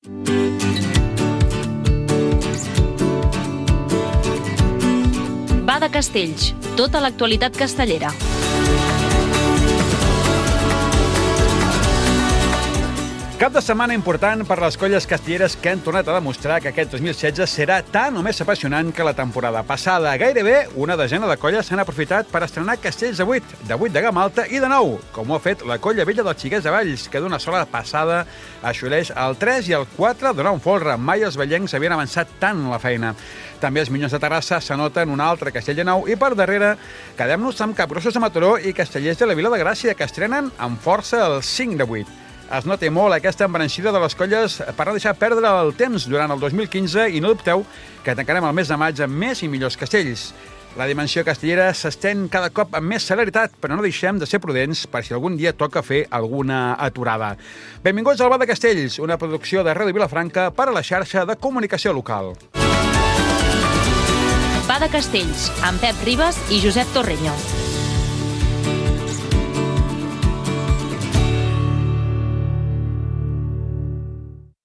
Careta i sumari informatiu de l'inici de la temporada de castells, indicatiu del programa amb els noms de l'equip